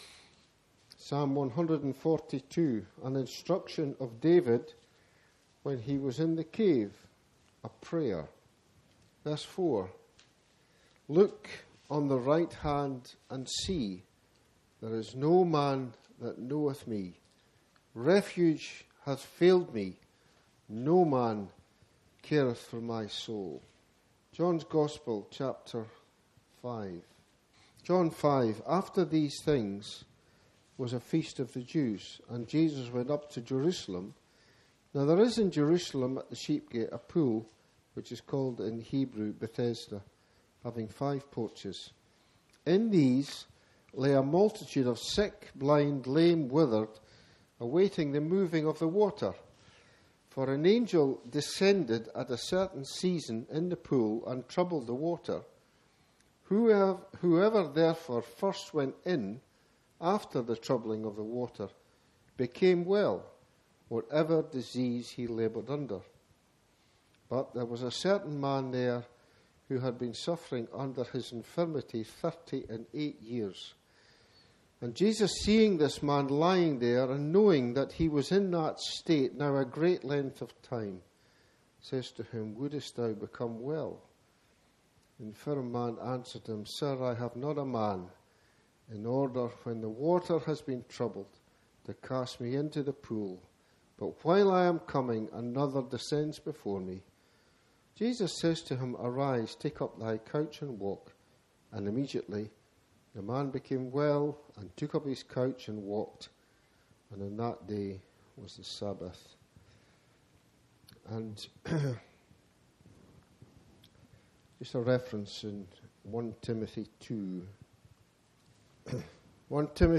This gospel message speaks to those who are feeling alone or burdened and reveals a Saviour who cares, understands, and draws near. At the cross, He gave Himself to deal with sin and open the way back to God, offering forgiveness and peace. It is a call to respond today—to receive salvation and find true rest by coming to Christ.